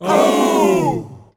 OUUUUH.wav